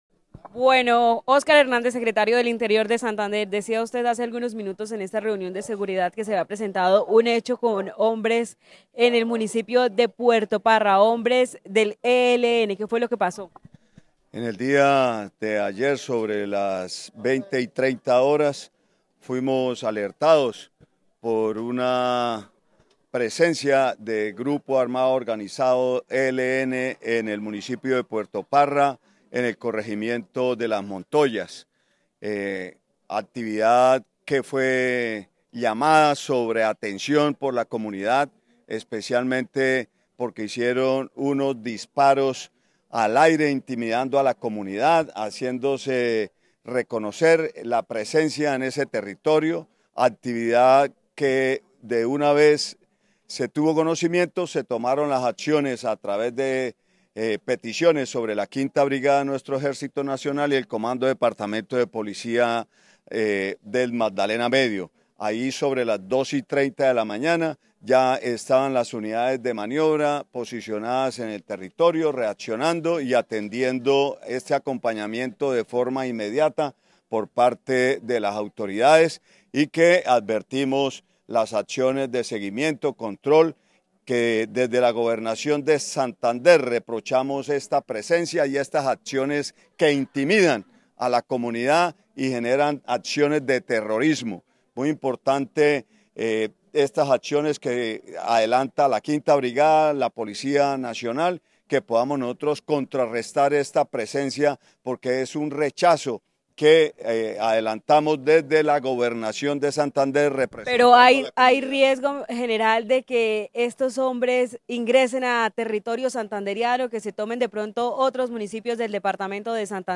Óscar Hernández, secretario del Interior de Santander